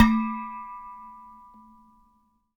bell_small_muted_03.wav